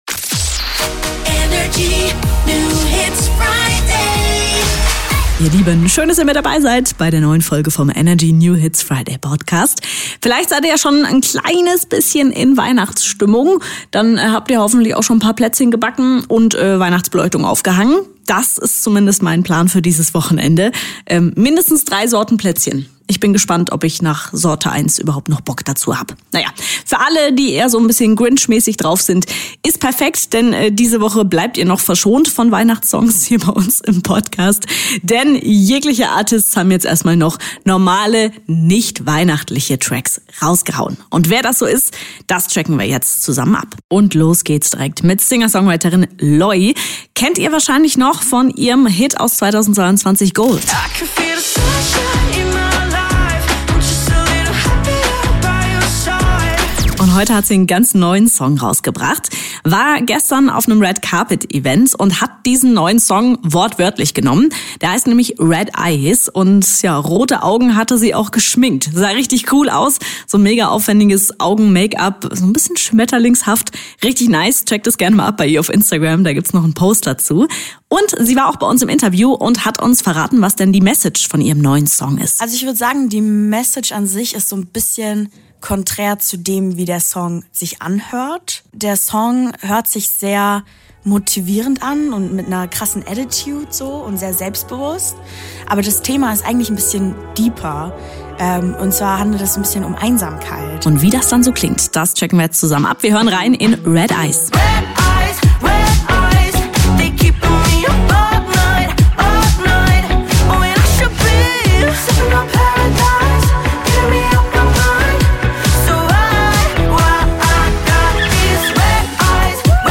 stellt brandneue hitverdächtige Songs vor.